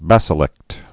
(băsə-lĕkt)